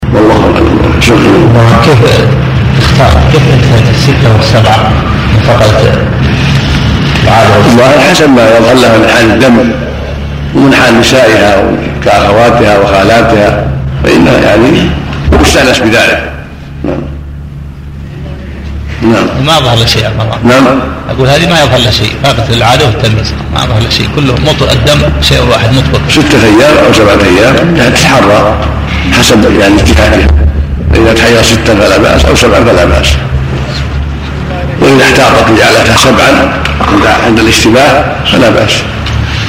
سلسلة محاضرات صوتية، وفيها تعليق الشيخ العلامة عبد العزيز بن باز - رحمه الله - على كتاب المنتقى من أخبار المصطفى - صلى الله عليه وسلم -، لمجد الدين أبي البركات عبد السلام بن تيمية الحراني - رحمه الله -.